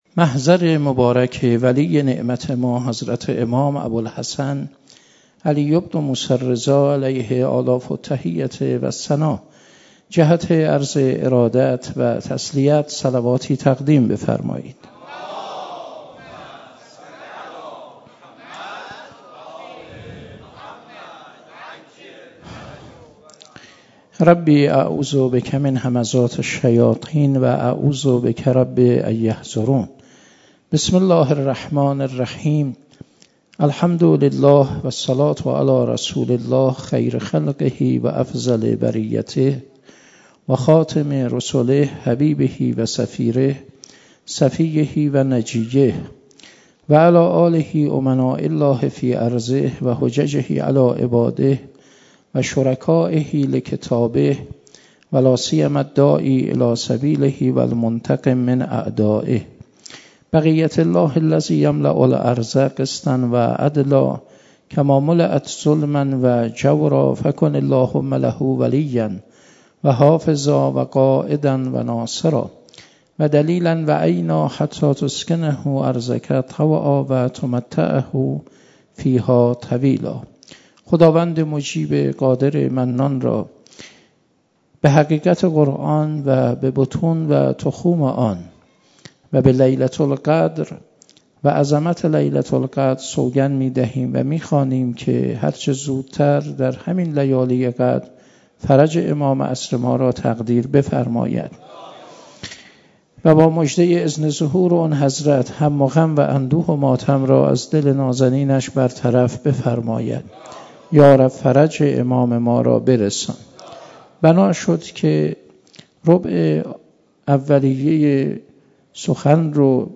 شب 20 رمضان 97 - هیئت عشاق الحسین - سخنرانی